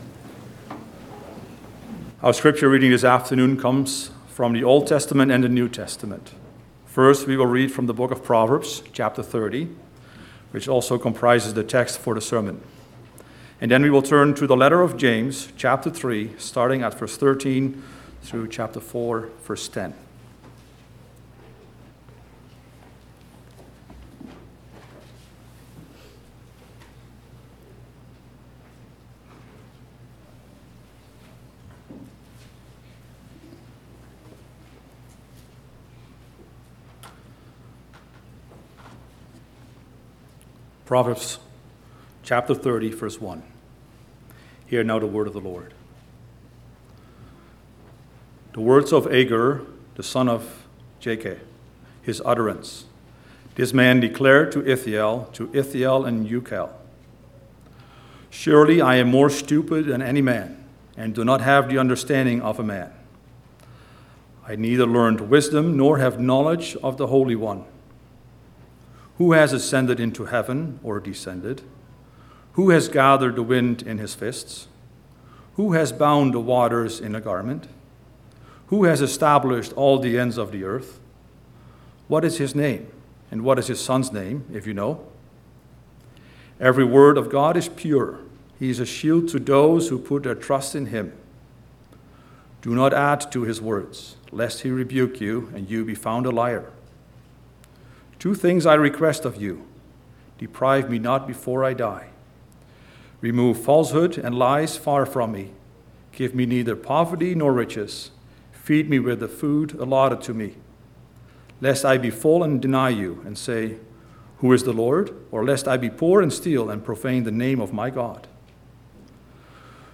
4.-Sermon-.mp3